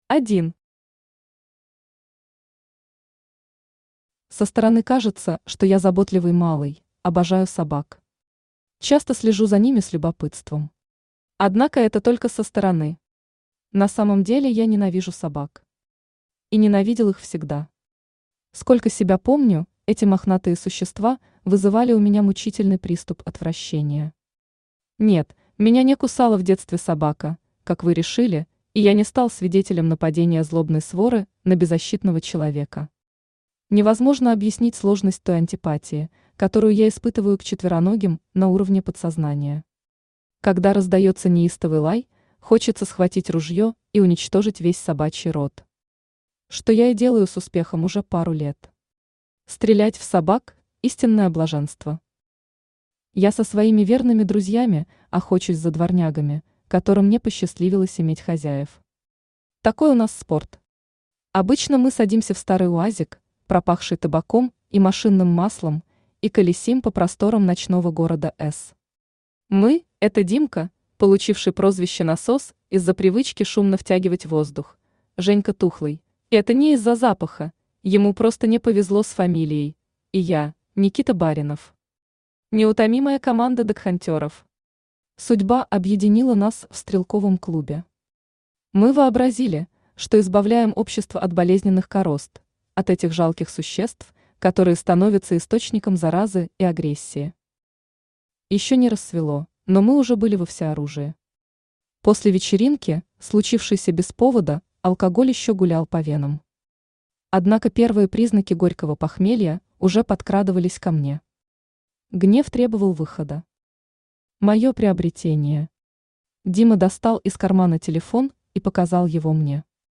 Аудиокнига Догхантер | Библиотека аудиокниг
Aудиокнига Догхантер Автор Н. Ланг Читает аудиокнигу Авточтец ЛитРес.